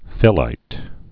(fĭlīt)